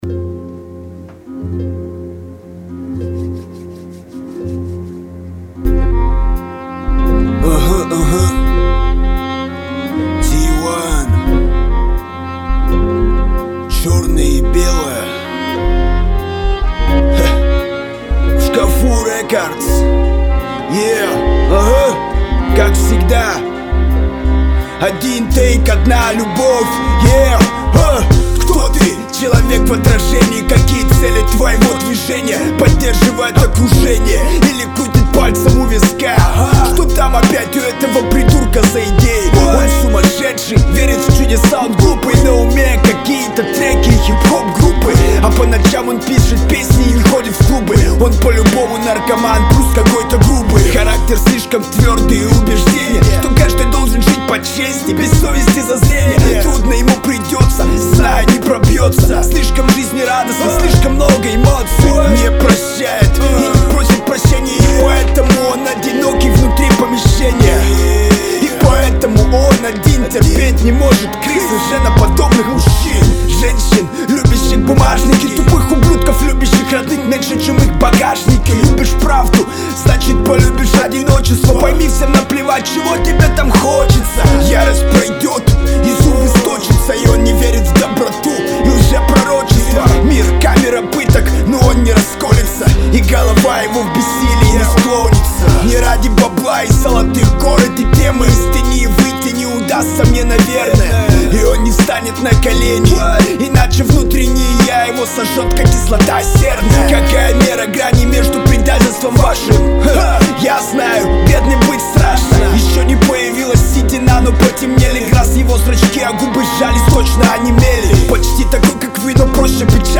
Категория: Рэп (Хип-хоп)